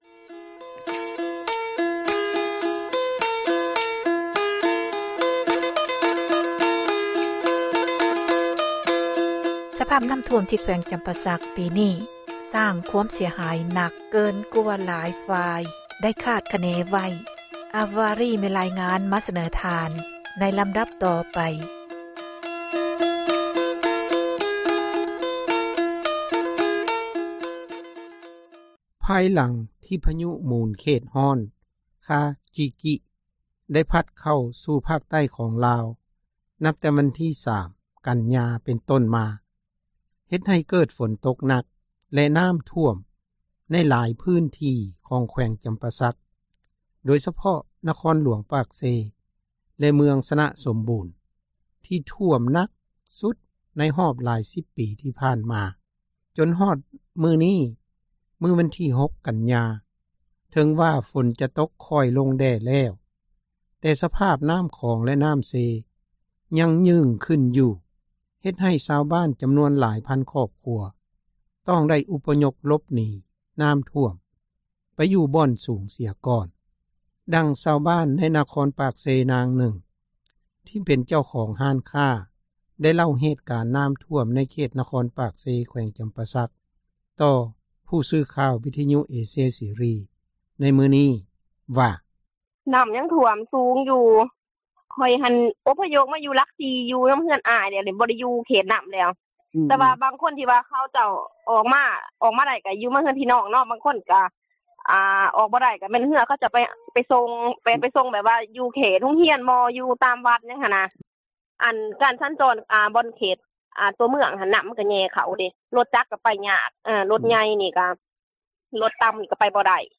ນາງກ່າວຕື່ມອີກວ່າ ເຫຕການນ້ຳຖ້ວມປີນີ້ ຖືວ່າໜັກສ່ຳກັບປີ 1978 ທີ່ໃນປີນັ້ນ ນ້ຳໄດ້ຖ້ວມເຖິງ ເຂດສນາມບິນປາກເຊ ຊາວບ້ານຕ້ອງໄດ້ ໜີນ້ຳຖ້ວມ ແບບຄຶດເອົາແຕ່ຊີວິຕ ແລະ ສັດລ້ຽງທີ່ລ້ຽງໄວ້ ຈຳນວນ ຫຼາຍ ກໍປະປ່ອຍໄປຕາມກະແສນ້ຳ. “ຊາວບ້ານໃນນະຄອນປາກເຊ.”
ທາງດ້ານເຈົ້າໜ້າທີ່ອາສາກູ້ພັຍ ໃນເຂດນະຄອນປາກເຊ ກໍໄດ້ໃຫ້ສັມພາດ ແກ່ຜູ້ສື່ຂ່າວພວກເຮົາ ເຊັ່ນດຽວກັນວ່າ ສະພາບການຊ່ອຍເຫຼືອ ແລະ ອົພຍົບ ປະຊາຊົນ ເປັນໄປຢ່າງຍາກລຳບາກ ພໍສົມຄວນ ເນື່ອງຈາກມີເຮືອບໍ່ພໍກັນ ແລະ ເຮືອທີ່ມີ ກໍເປັນເຮືອທີ່ນ້ອຍ ບໍ່ສາມາດ ຕ້ານທານ ກັບກະແສນ້ຳທີ່ໄຫຼແຮງໄດ້ ເພື່ອໄປຊ່ອຍຊາວບ້ານ ທີ່ຕິດຢູ່ຕາມຫຼັງຄາເຮືອນໄດ້ ຕ້ອງໄດ້ຖ້າອາໃສ ແຕ່ເຮືອໃຫຍ່ຂອງ ກອງທັບ. “ເຈົ້າໜ້າທີ່ ອາສາກູ້ພັຍ ໃນເຂດນະຄອນປາກເຊ.”